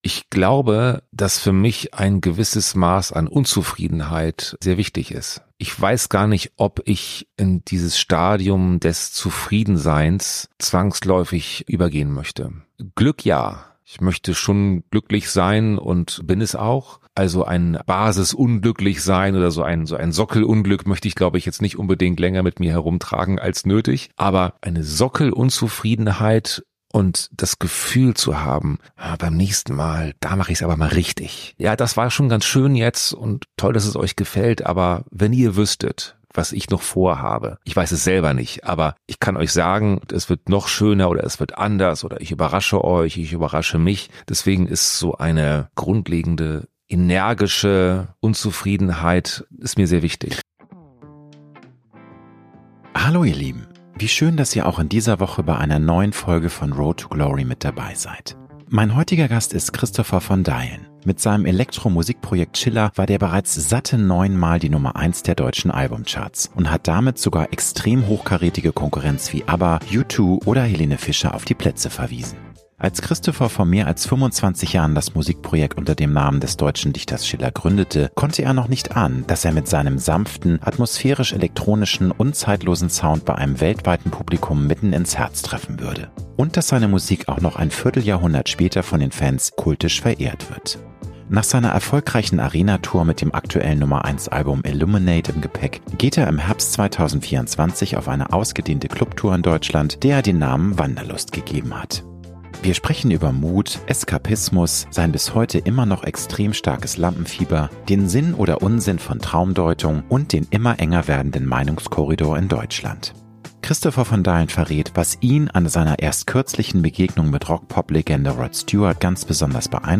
Promi-Talk
Mein heutiger Gast ist Christopher von Deylen: